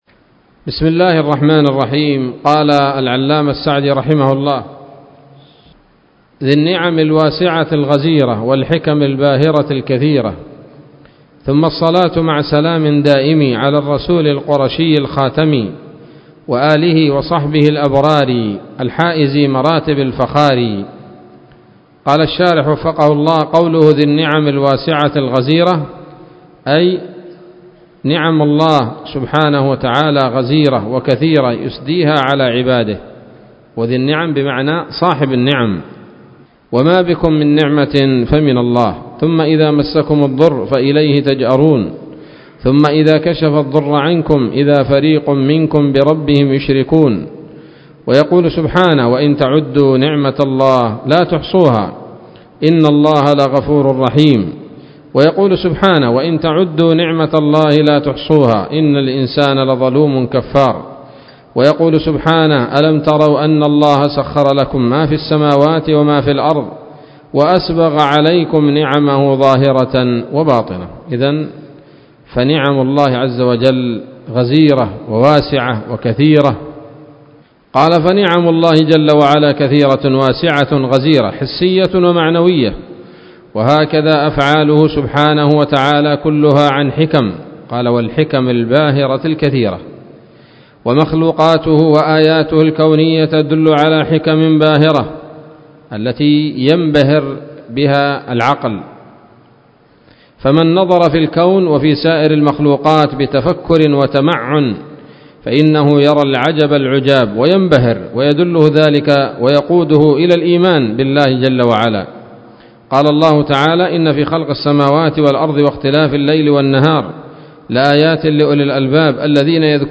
الدرس السادس من الحلل البهية في شرح منظومة القواعد الفقهية